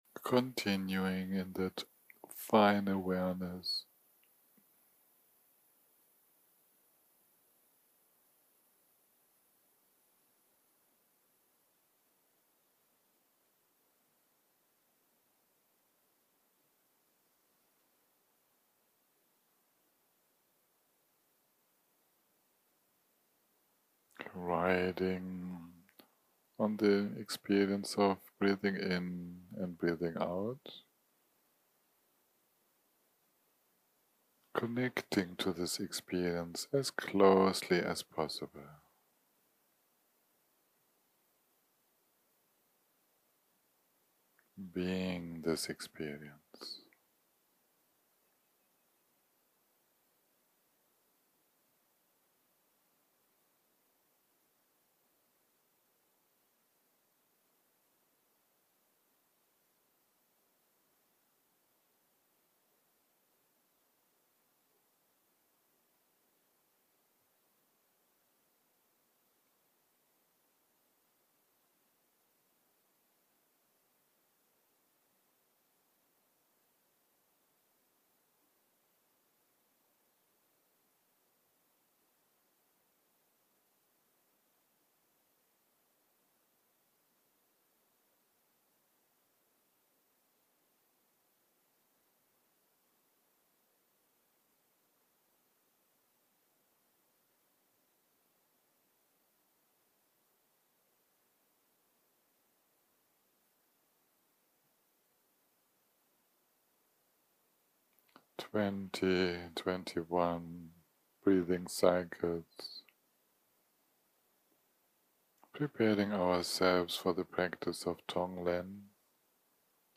יום 9 - הקלטה 43 - בוהריים - מדיטציה מונחית - Tong Len 8